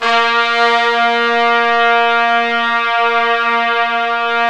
Index of /90_sSampleCDs/Roland LCDP06 Brass Sections/BRS_Tpts mp)f/BRS_Tps Velo-Sw